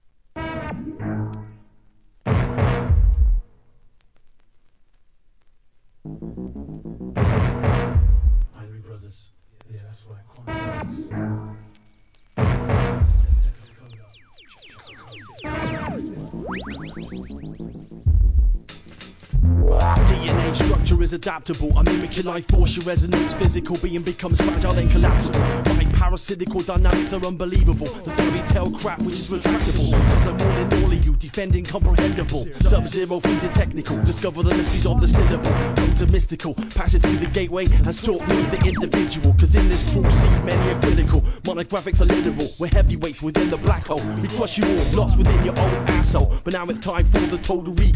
1. HIP HOP/R&B
1999年、UKアンダーグランド!!